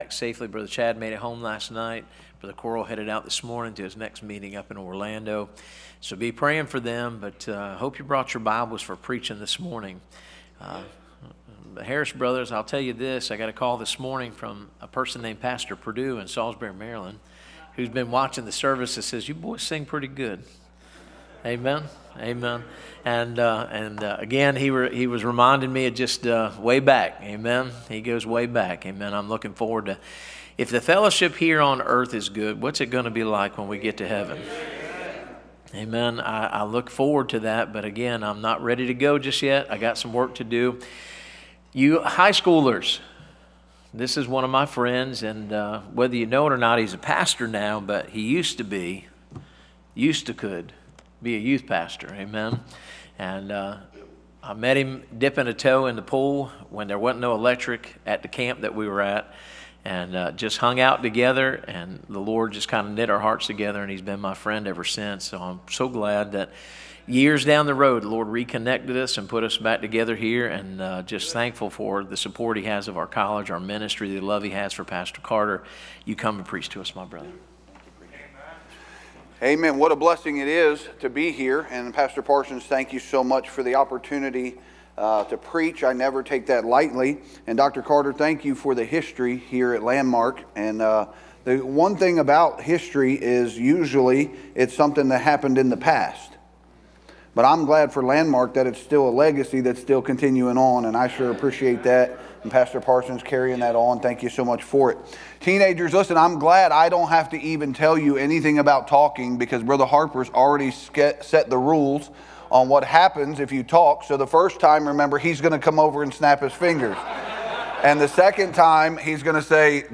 Series: 2025 Bible Conference What's It Gonna Take For God To Refuse The Church Wednesday, January 22, 2025 · BC-8 · 14 min Listen (audio only):